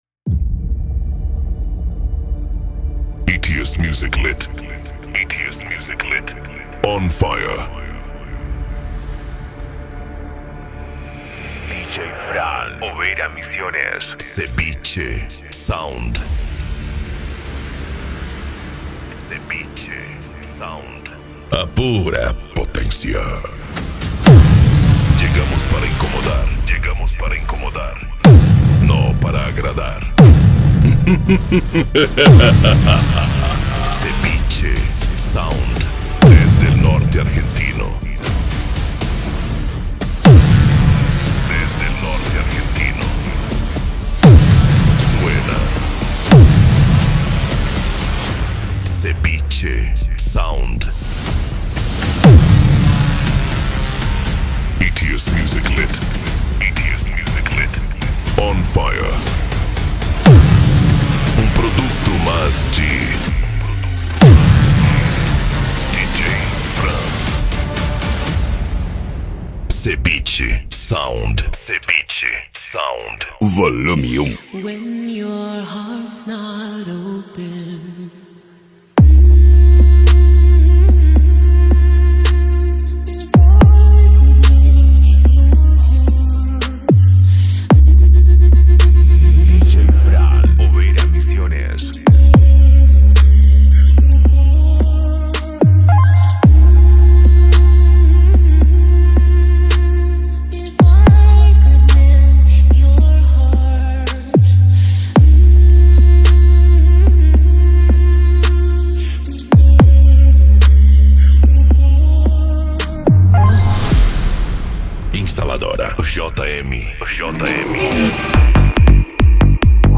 Remix
Racha De Som
Bass